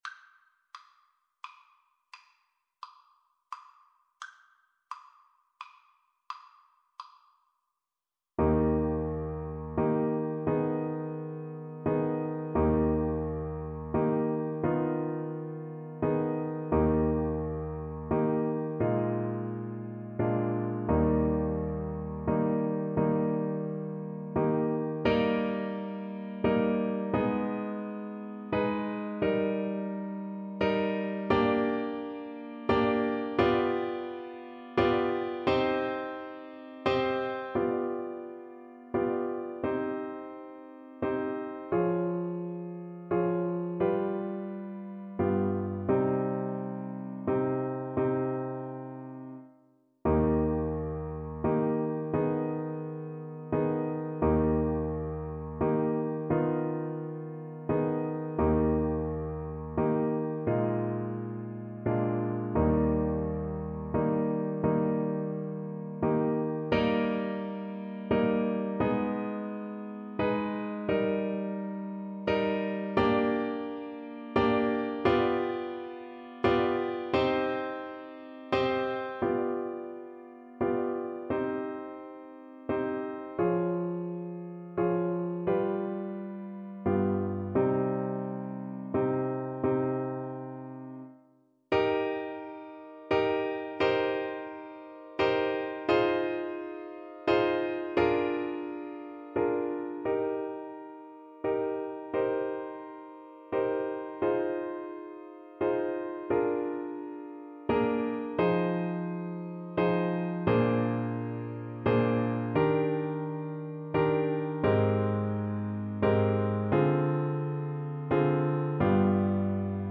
Classical Paradis, Maria Theresia von Sicilienne Clarinet version
Play (or use space bar on your keyboard) Pause Music Playalong - Piano Accompaniment Playalong Band Accompaniment not yet available transpose reset tempo print settings full screen
6/8 (View more 6/8 Music)
Bb4-Db7
Eb major (Sounding Pitch) F major (Clarinet in Bb) (View more Eb major Music for Clarinet )
Andantino .=c.45 (View more music marked Andantino)
Clarinet  (View more Intermediate Clarinet Music)
Classical (View more Classical Clarinet Music)